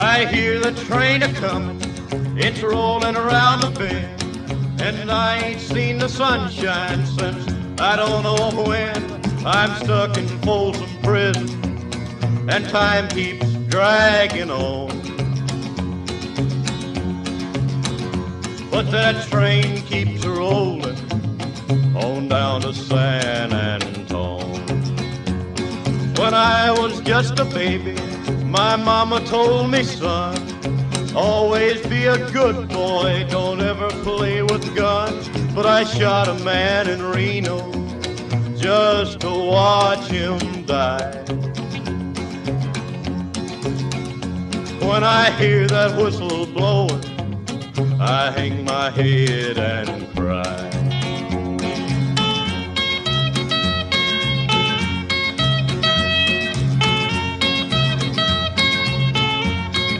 Here rounding the bend is my Southern 4501 carrying frieght train.